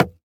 bamboo_wood_button.ogg